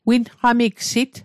Pronunciation Guide: win·hka·mik·sit